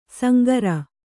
♪ sangara